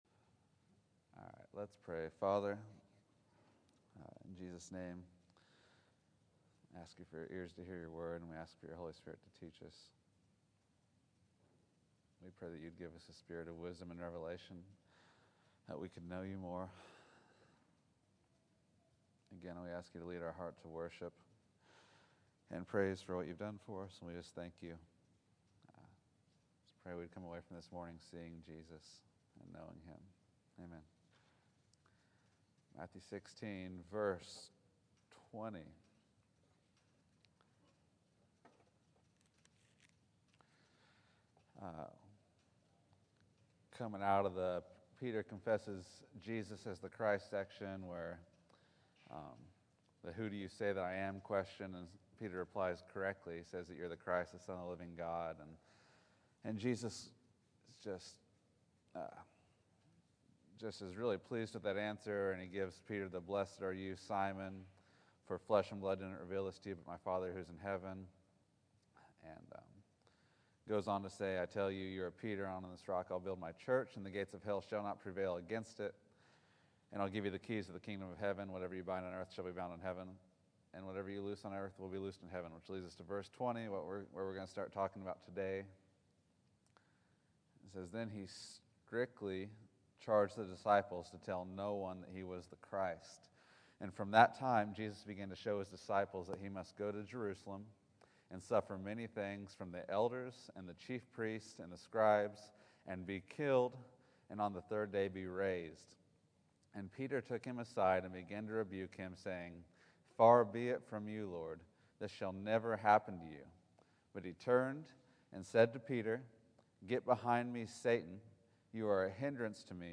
Matthew 16:20-23 November 24, 2013 Category: Sunday School | Location: El Dorado Back to the Resource Library Peter rebukes Jesus and gets rebuked.